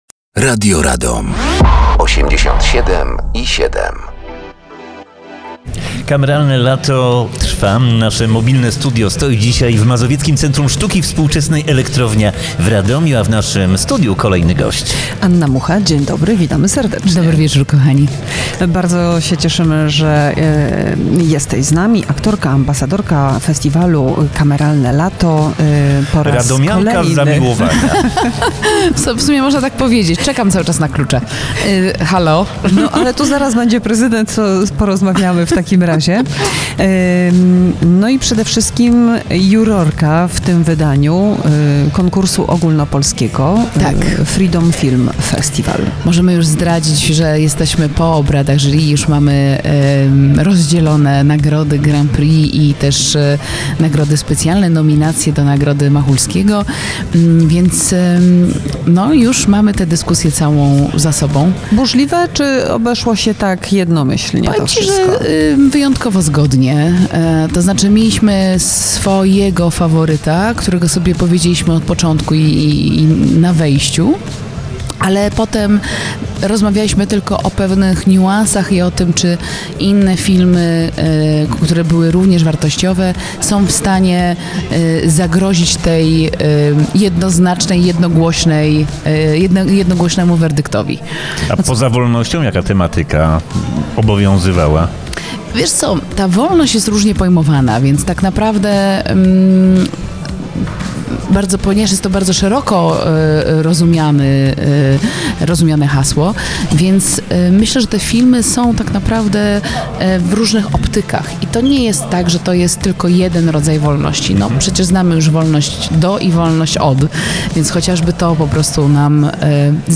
Gościem Kameralnego Lata jest Anna Mucha.